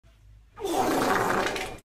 Sound Effects
Wet Fart Noise